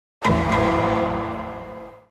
Category: Sound FX   Right: Personal